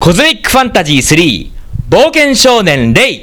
voice saying "Cosmic Fantasy 3: Boken Shonen Rei" (reproduced here as a 29.6KB mono RealAudio recording), the title screen, and the theme song.